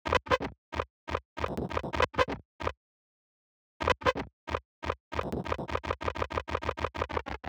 Fuel_128 – Synth_2
FUEL_-3-Fuel_128-Synth_2.mp3